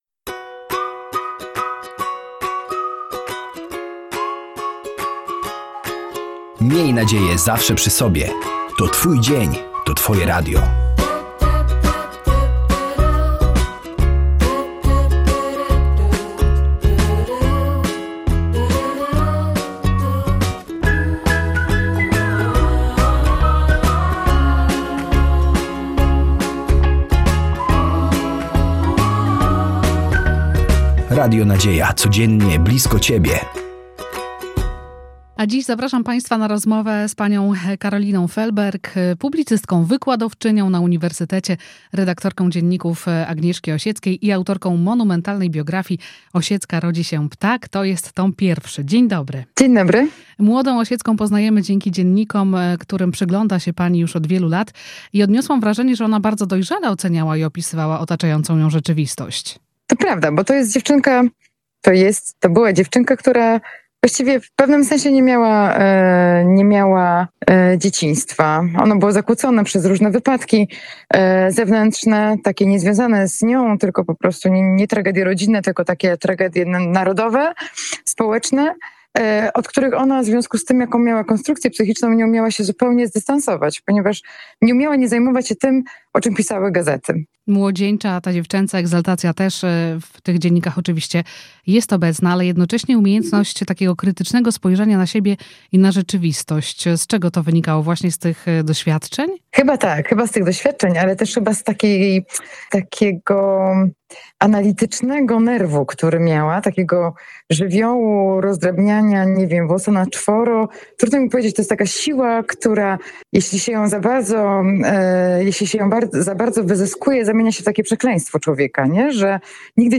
Kiedy przestała mieć złudzenia co do sprawiedliwości społecznej? Zapraszamy do wysłuchania rozmowy.